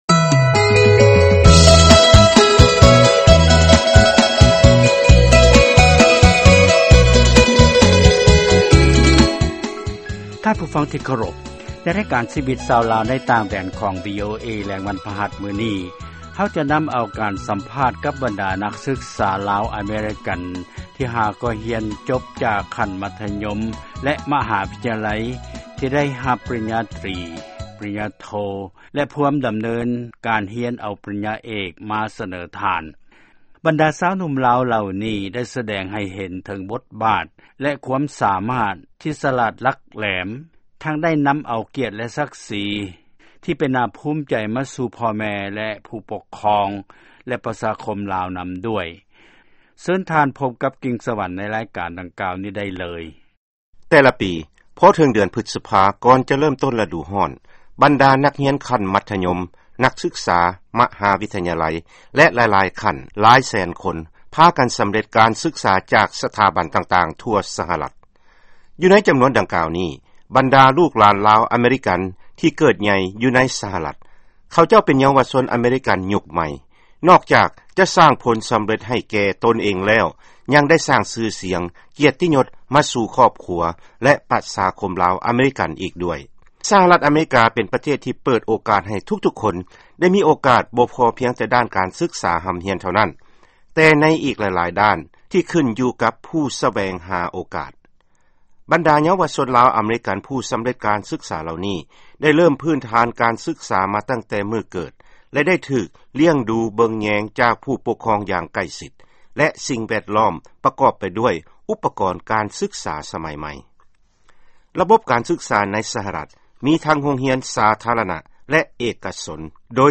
ຟັງການສໍາພາດ ບັນດານັກສຶກສາ ລາວ-ອາເມຣິກັນ ທີ່ສໍາເລັດ ໃນປີ 2013